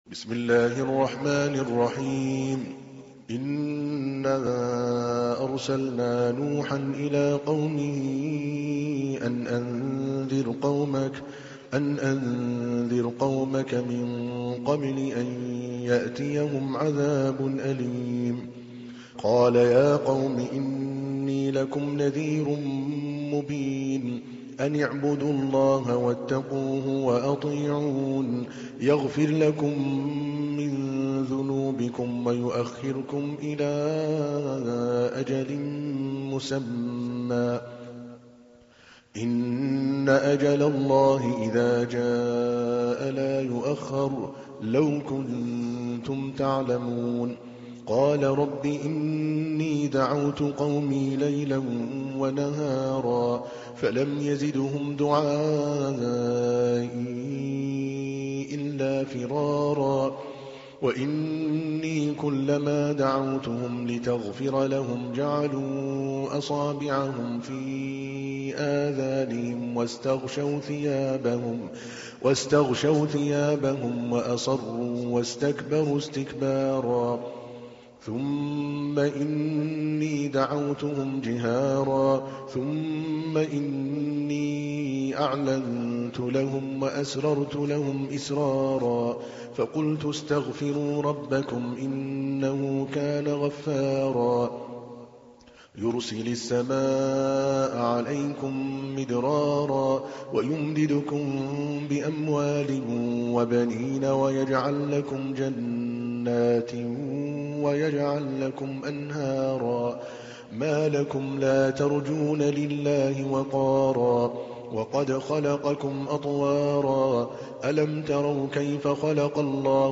تحميل : 71. سورة نوح / القارئ عادل الكلباني / القرآن الكريم / موقع يا حسين